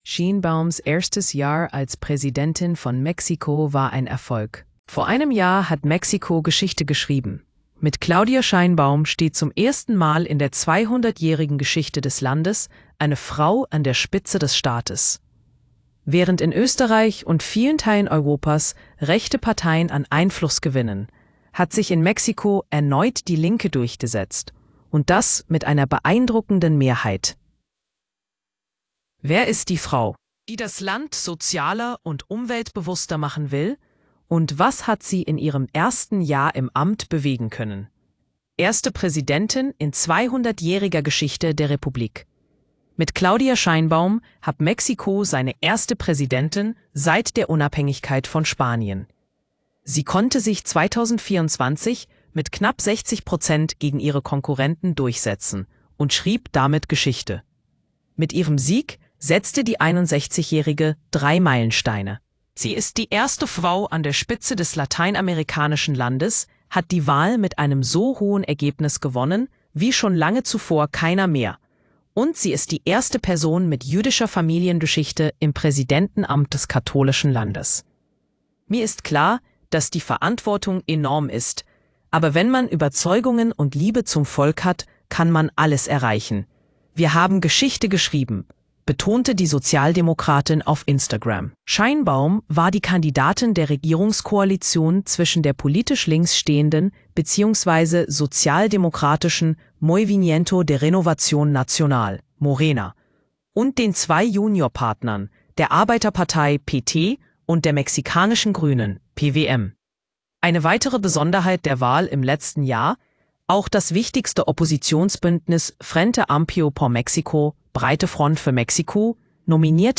Hallgassa meg az cikk hangos változatát (AI által generált).